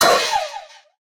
1.21.4 / assets / minecraft / sounds / mob / allay / hurt2.ogg
hurt2.ogg